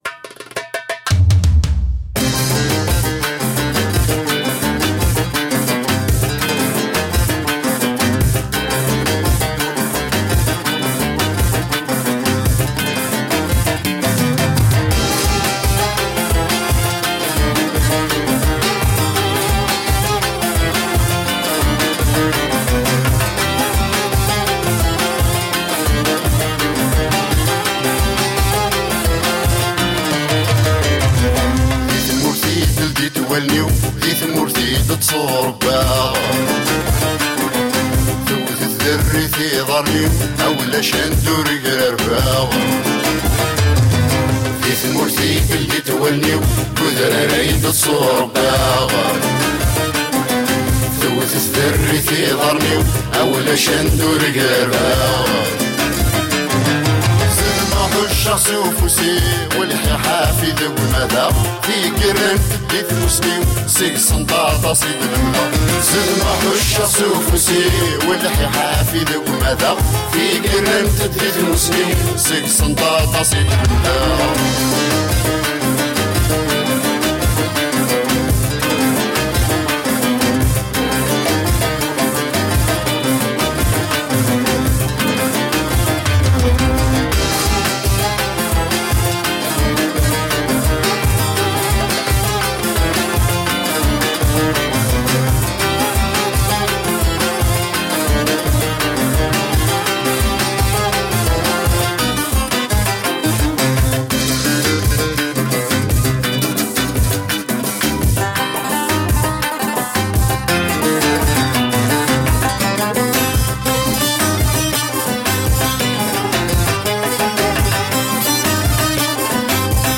Music of the mountains of kabylia.
Tagged as: World, Folk, Arabic influenced, World Influenced